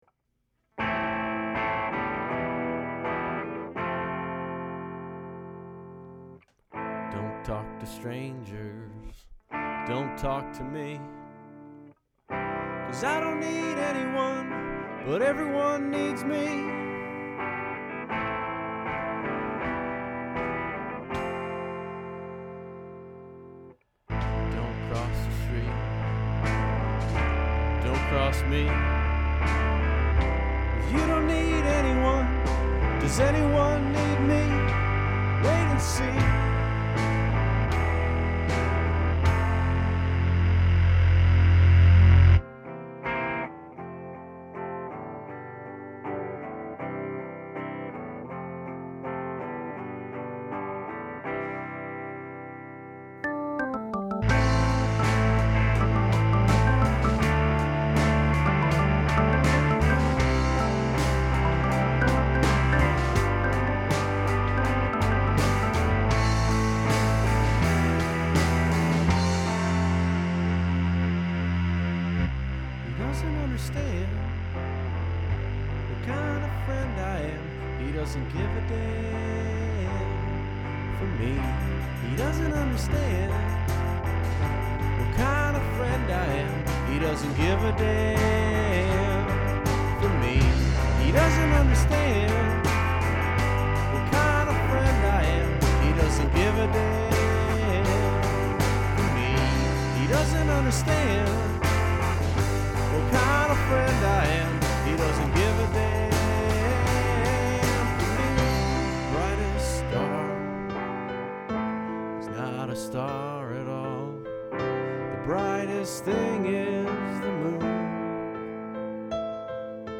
A little "suite"
cool synth bass, organ